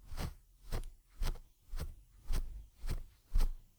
grass_footsteps.wav